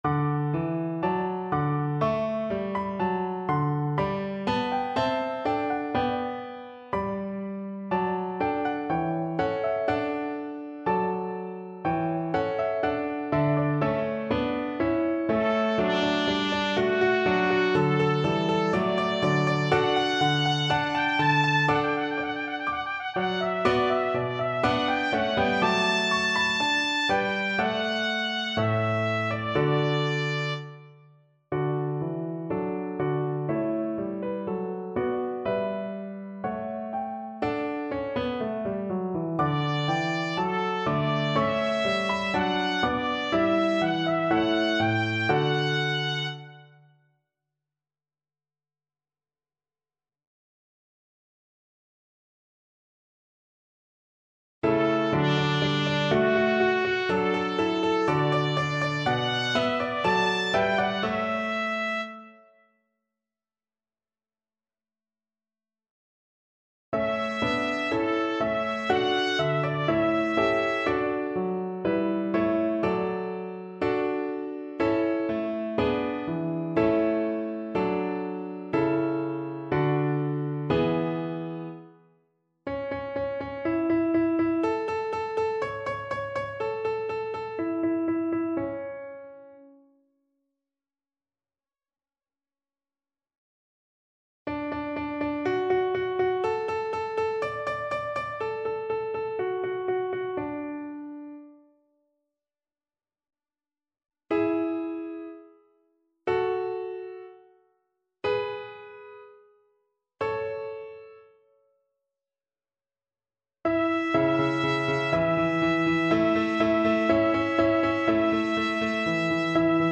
Soprano VoiceTrumpet
4/4 (View more 4/4 Music)
Moderato
Classical (View more Classical Soprano Voice Music)